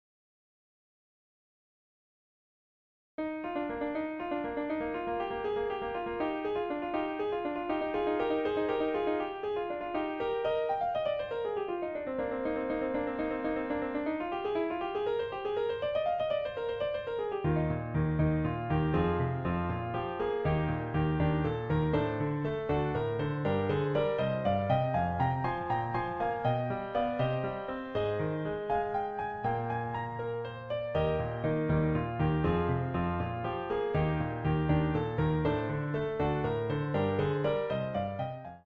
A Intermediate Piano Song!